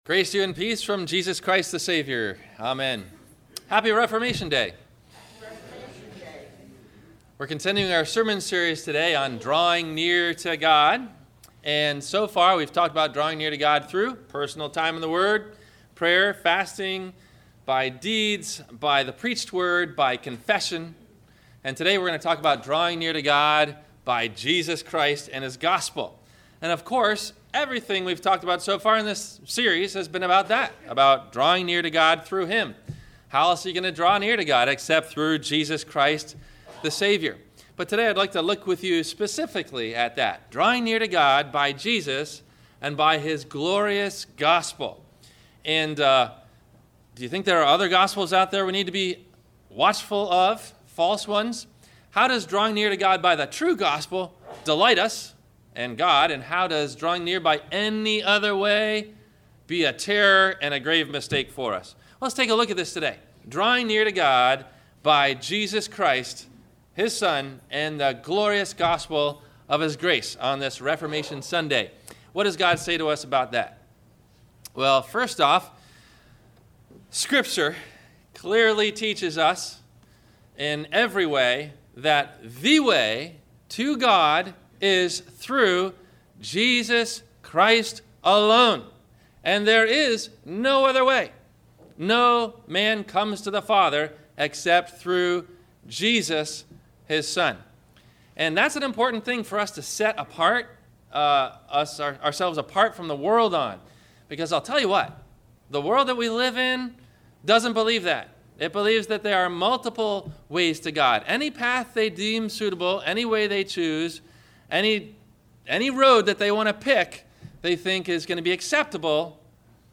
How To Draw Near To God Through Jesus and His Gospel – Reformation Sunday – Sermon – October 26 2014